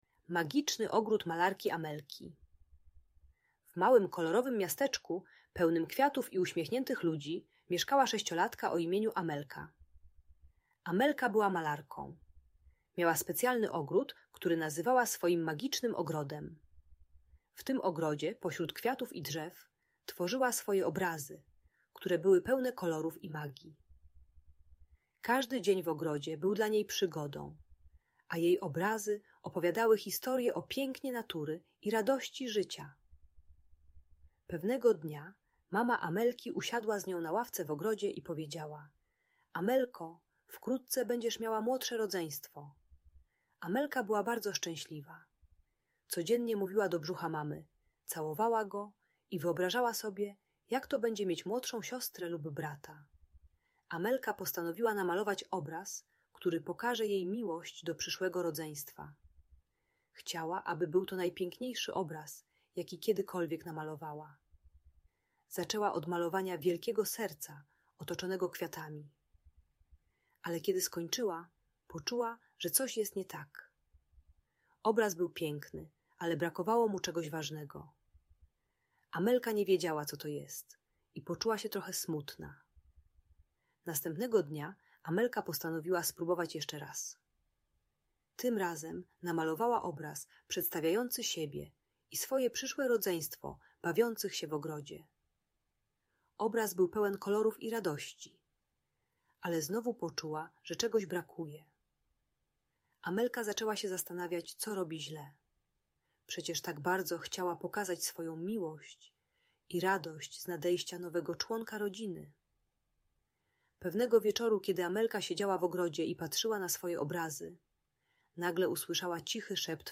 Pomaga przygotować starsze dziecko na narodziny brata lub siostry. Uczy techniki dzielenia się miłością i przestrzenią z nowym członkiem rodziny. Audiobajka o akceptacji rodzeństwa i radzeniu sobie z zazdrością.